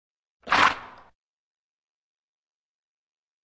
Лошадь фыркает
Звук, похожий на чихание, но это просто короткое фырканье